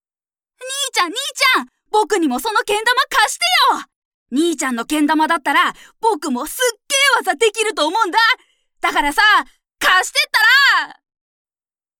ボイスサンプル
台詞